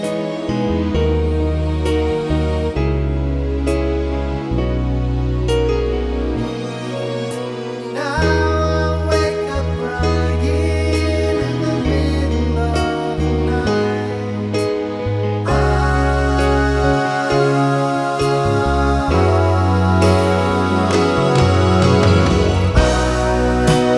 trimmed Pop (1980s) 3:32 Buy £1.50